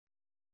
♪ kaḷedōṛu